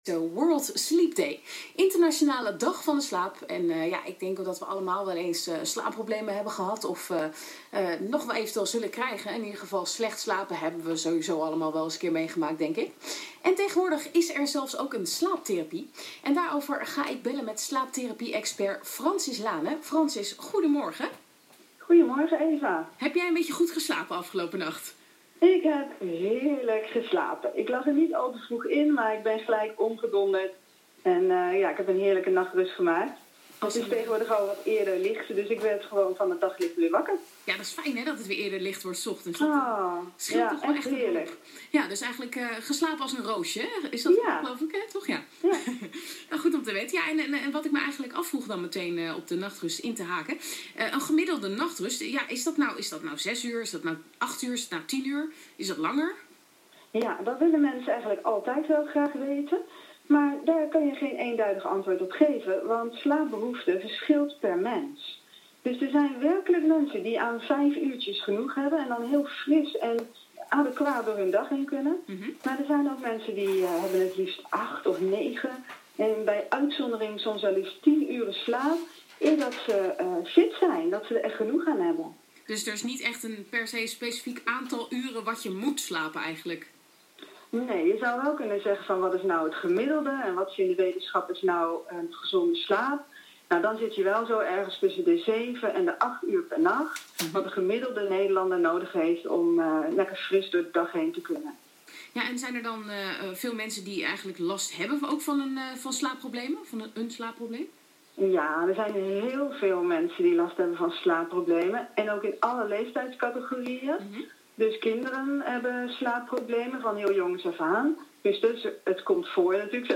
Radio Interview Amsterdam FM - Wereld slaap dag
18_mrt_wereld_slaap_dag_radio_interview_amsterdam.mp3